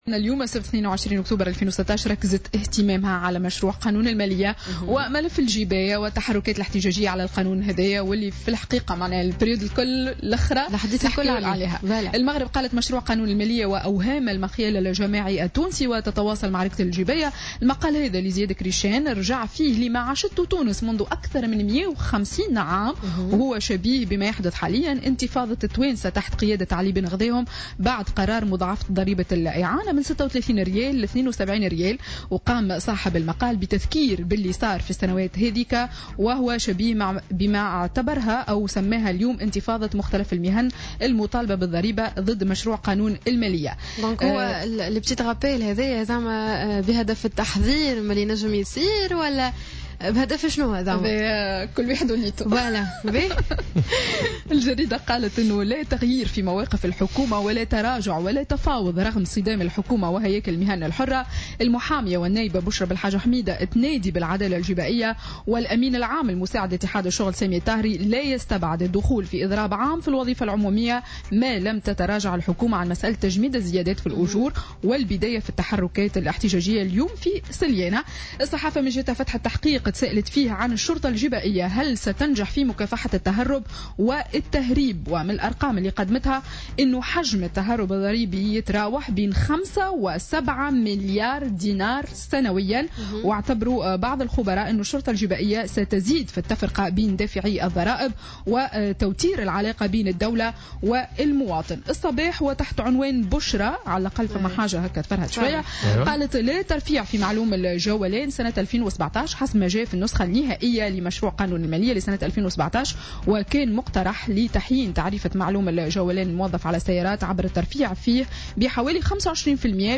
Revue de presse du samedi 22 Octobre 2016